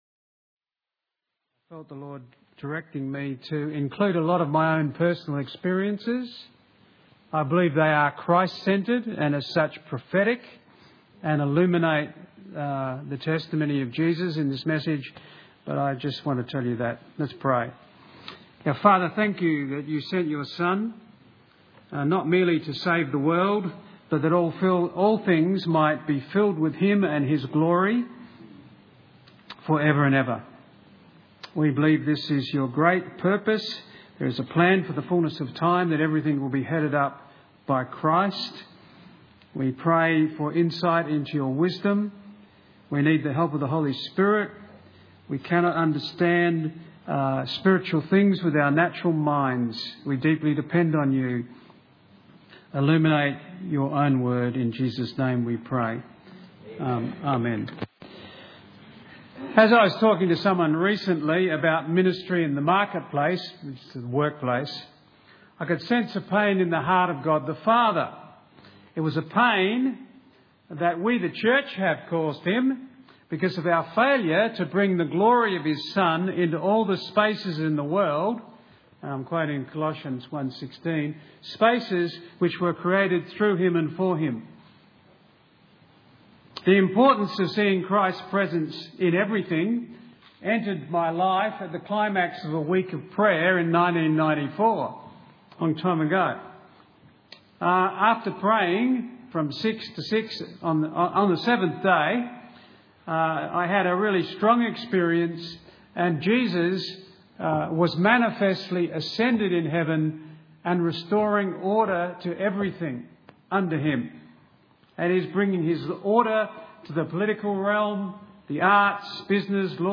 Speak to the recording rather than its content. Guest Speaker Service Type: Sunday Morning « The 5 Fold Gifts P7